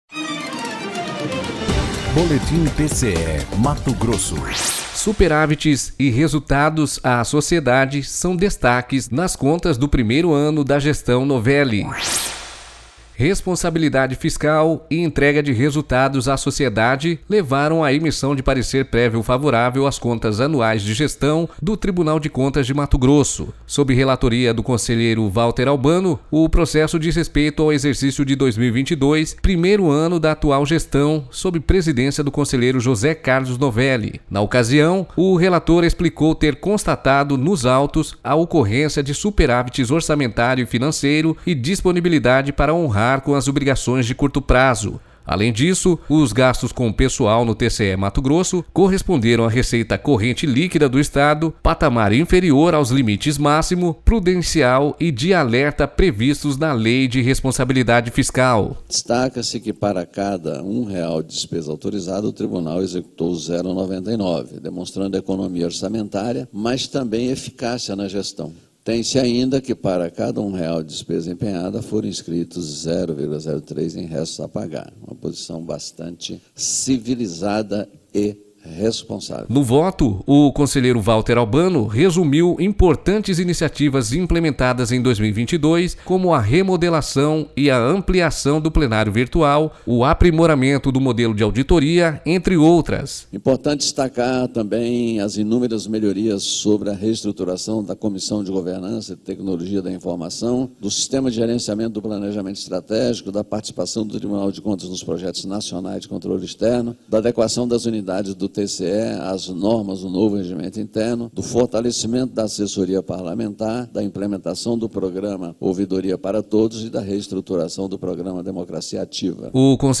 Sonora: Valter Albano – conselheiro do TCE-MT
Sonora: Alisson Carvalho de Alencar - procurador-geral do MPC-MT